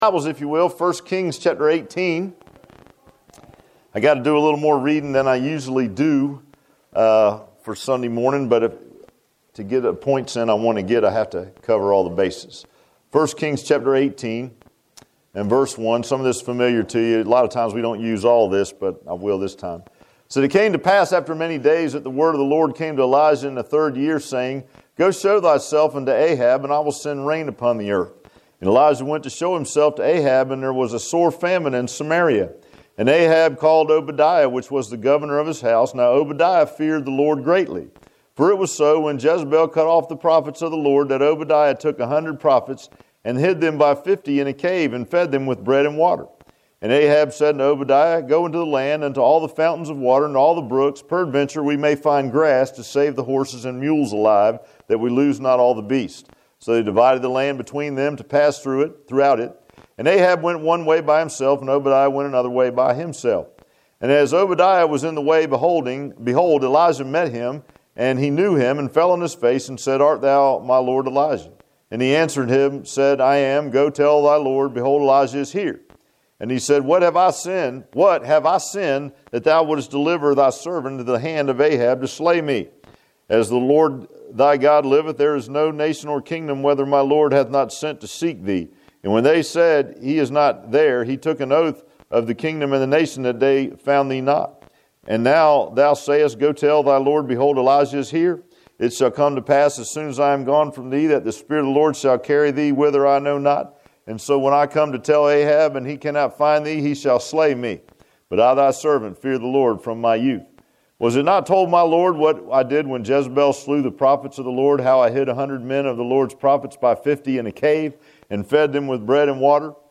I Kings 18:1-39 Service Type: Sunday AM Bible Text